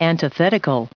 added pronounciation and merriam webster audio
64_antithetical.ogg